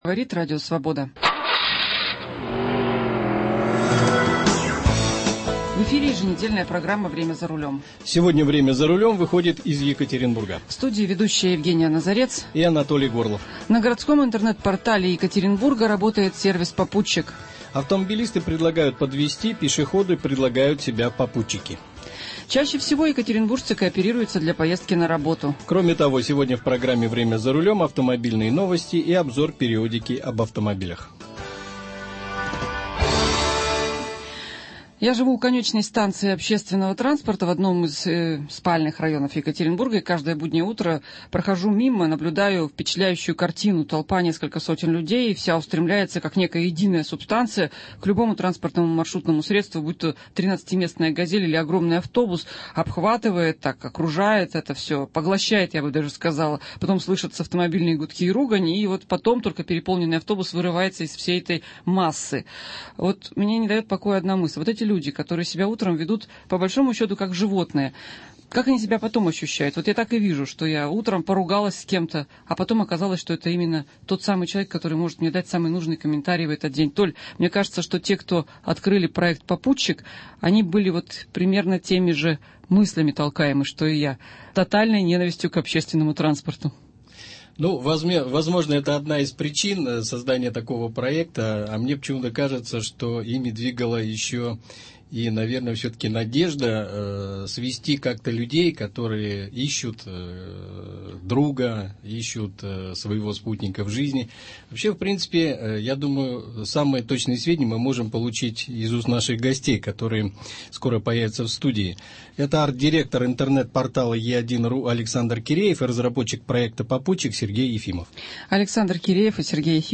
В студии программы «Время за рулем»